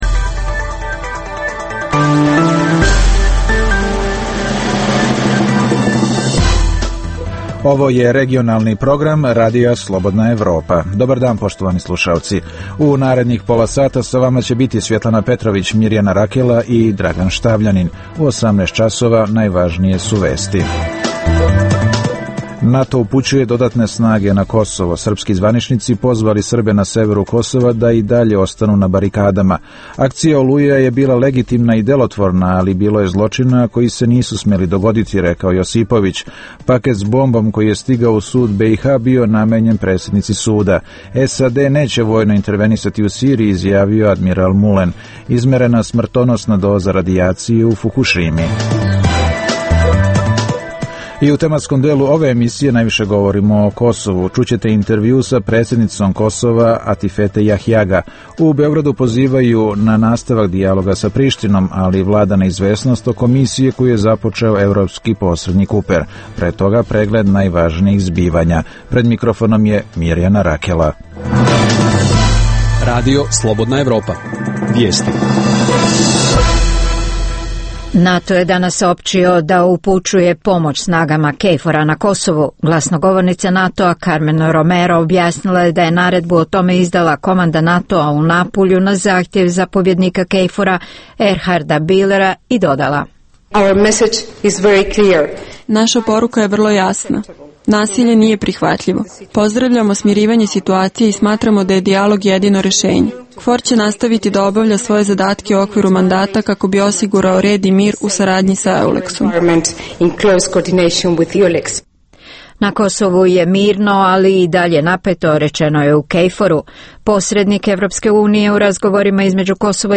U emisiji možete čuti: - Intervju sa predsednicom Kosova Atifete Jahjaga. - U Beogradu pozivaju na nastavak dijaloga sa Prištinom, ali vlada neizvesnost oko misije koju je započeo evropski posrednik Kuper.